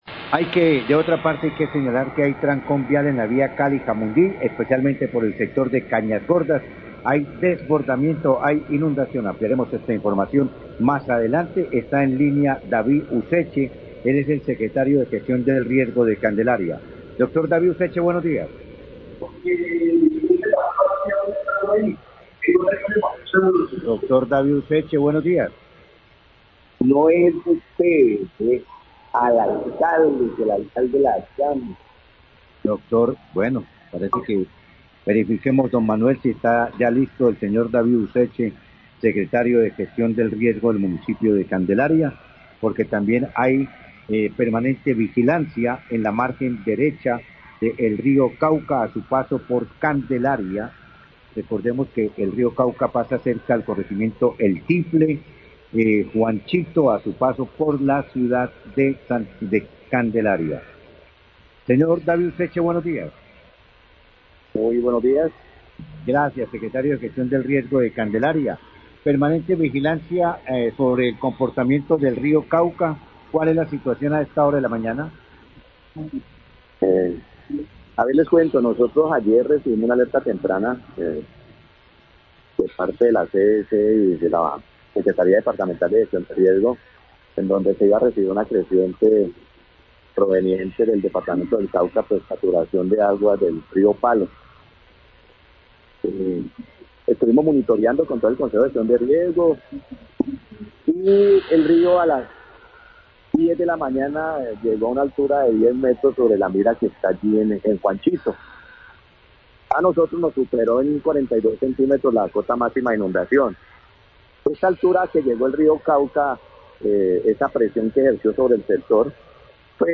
Radio
Secretario de Gestión de Riesgo de Candelaria habló de la alerta temprana que se recibió por parte de la CVC sobre creciente del río Cauca, que permitió hacer el despliegue de los organismo de socorro para cerrar vías debido a las inundaciones que se presentaron. Manifestó que la creciente ya pasó y están atentos a los reportes de la CVC para las próximas horas.